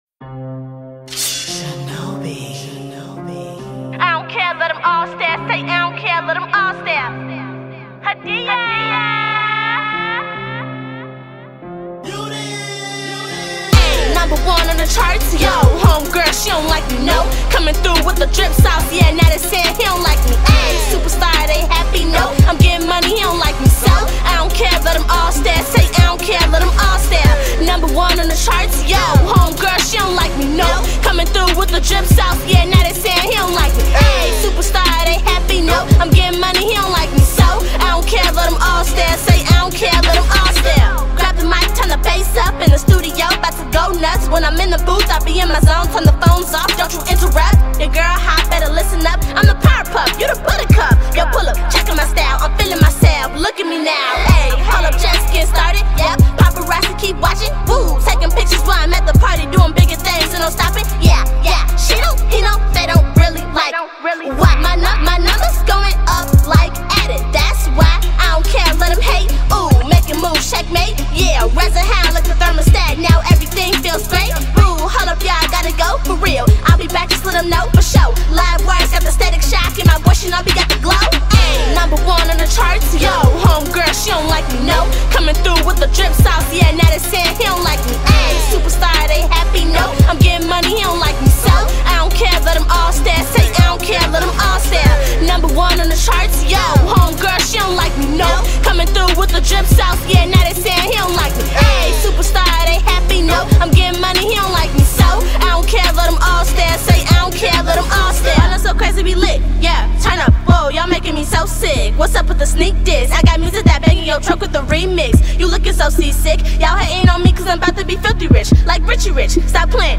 Hiphop
Aggressive, lyrical hip hop/pop that's totally lit!